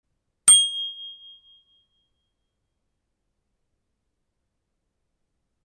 Ringeklokken er nem at montere, og giver en høj og klar lyd, så alle kan høre dig når du kommer kørende.
• OnGear Ringeklokke til cykel
• Type: Fjederhammer
• Materiale: Plast understel med gummibånd. Lakeret stålklokke